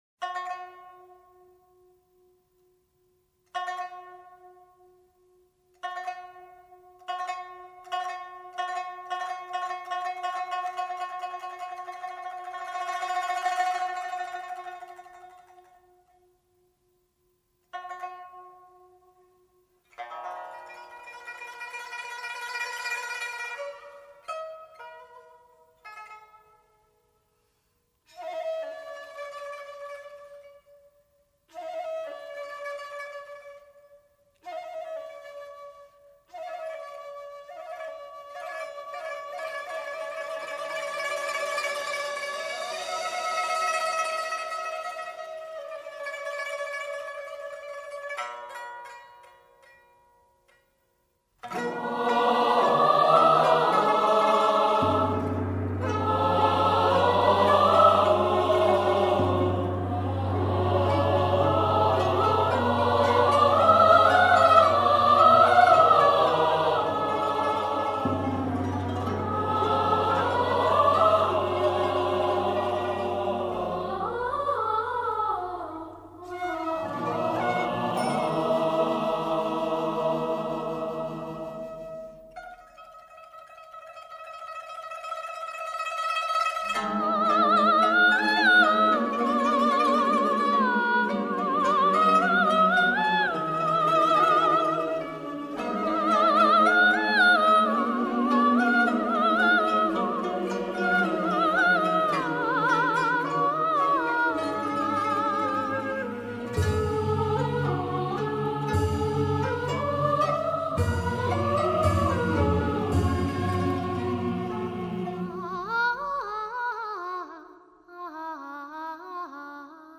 男女声合唱